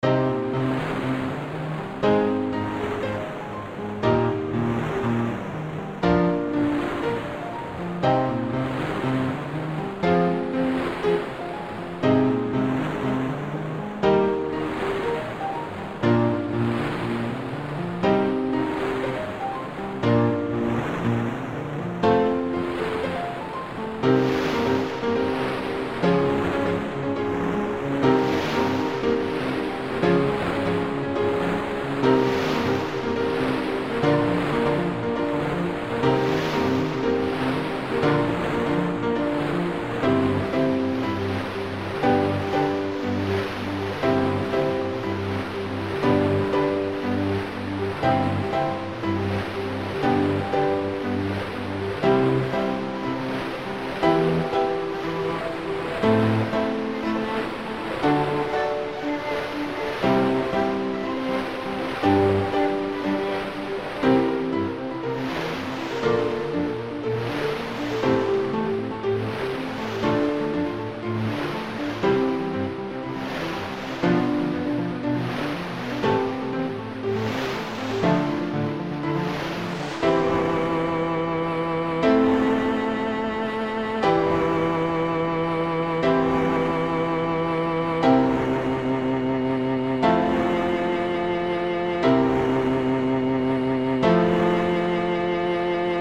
BGM
シーショア、ピアノ、ビオラ